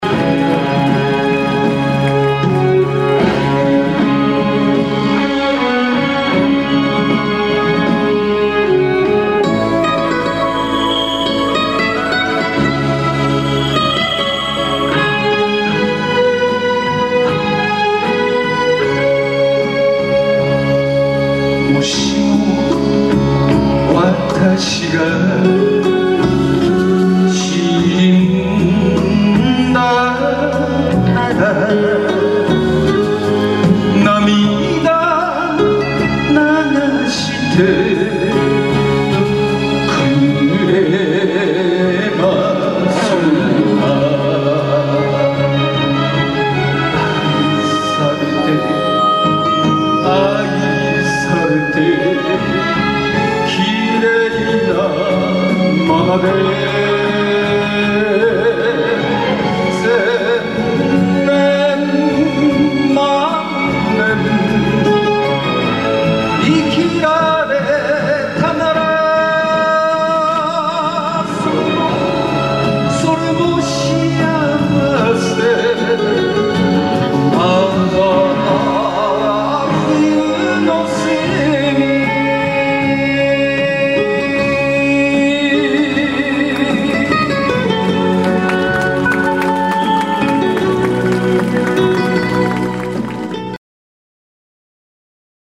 第１3回教室発表会